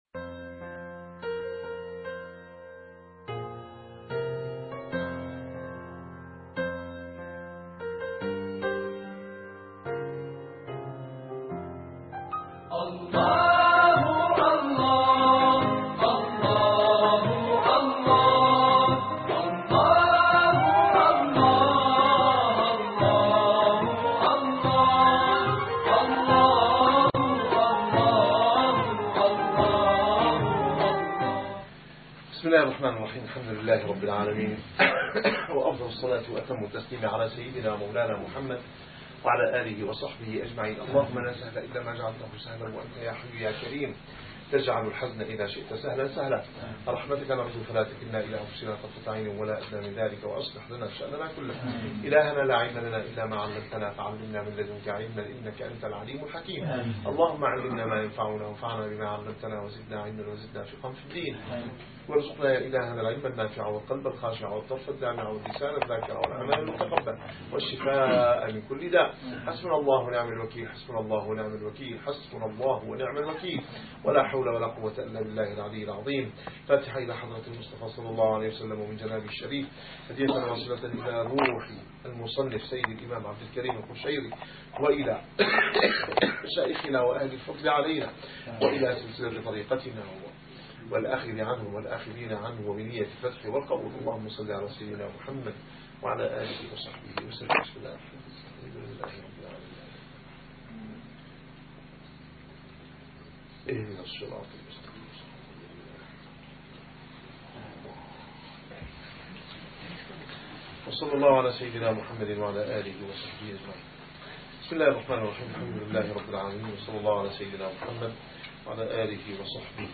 - الدروس العلمية - الرسالة القشيرية - الرسالة القشيرية / الدرس السادس بعد المئة.